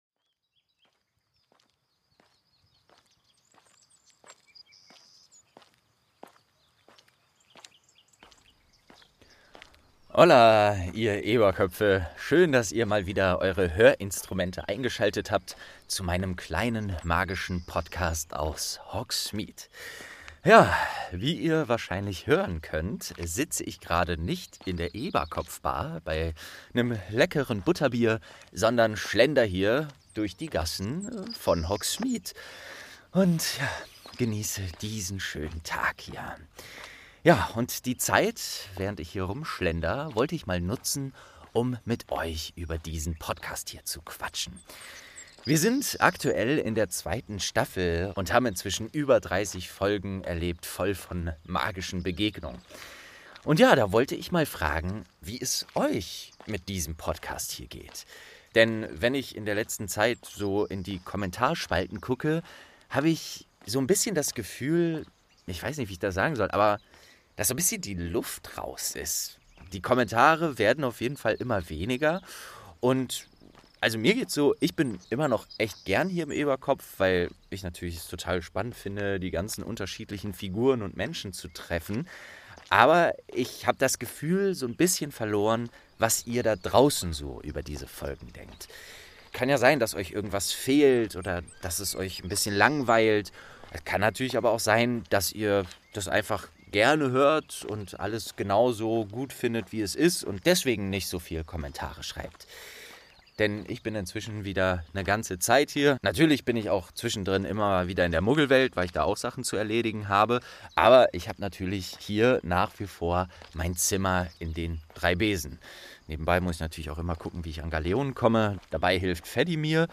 St. 2 ~ Geschichten aus dem Eberkopf - Ein Harry Potter Hörspiel-Podcast Podcast